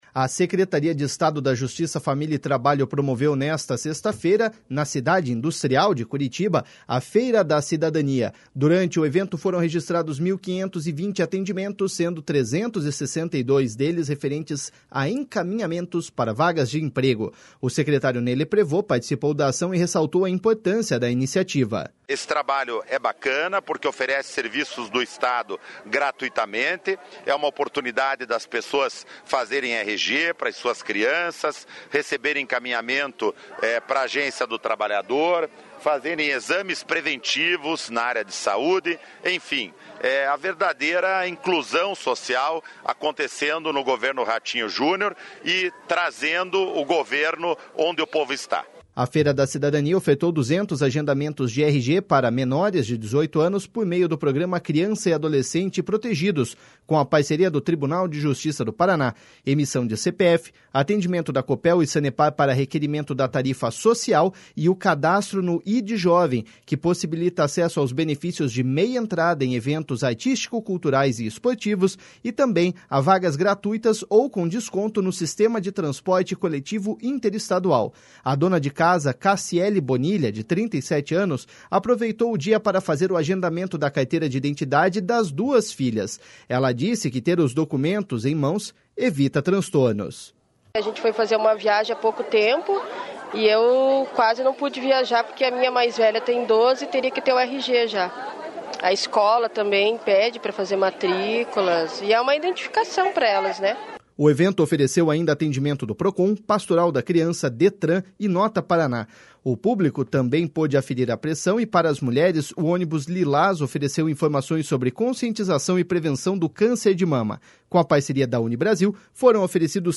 Durante o evento foram registrados 1.520 atendimentos, sendo 362 deles referentes a encaminhamentos para vagas de emprego. O secretário Ney Leprevost participou da ação e ressaltou a importância da iniciativa.// SONORA NEY LEPREVOST.//